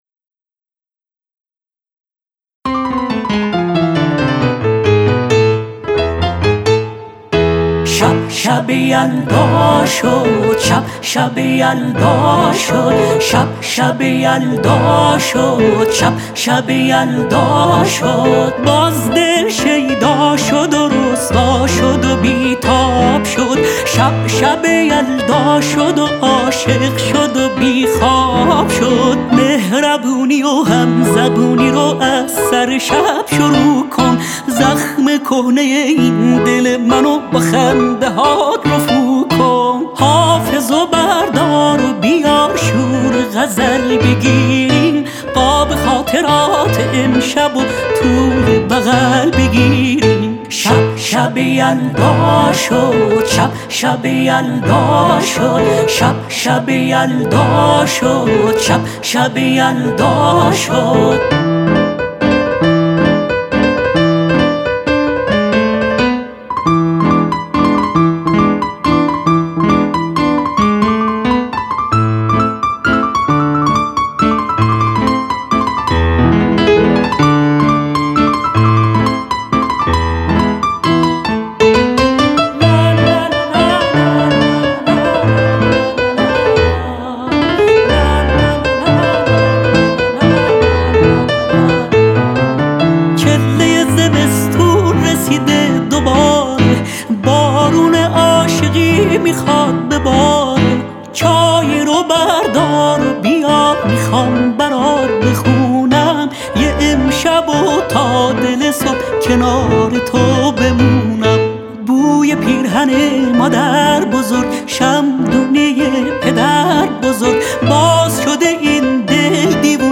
آهنگ هاي شاد